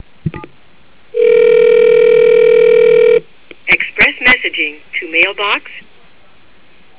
Typical express login message Main Keypad